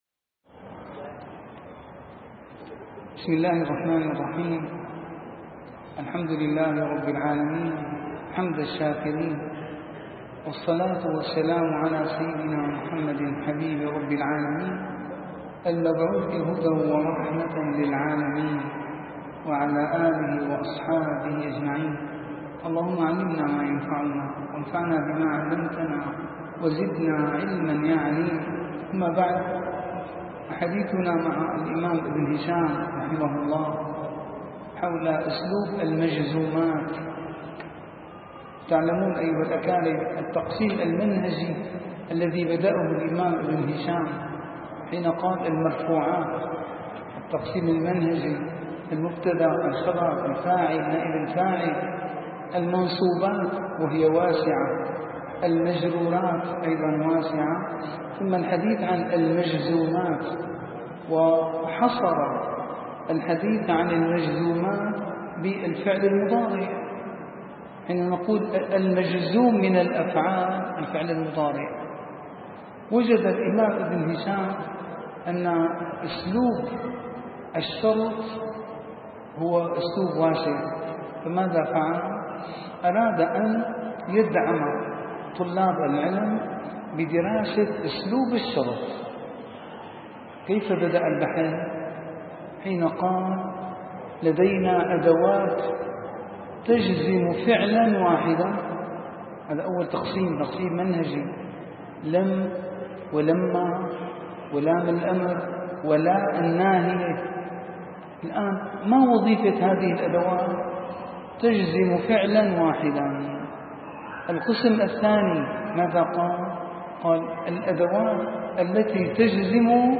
- الدروس العلمية - شرح كتاب شذور الذهب - 100- شرح كتاب شذور الذهب:أسلوب الشرط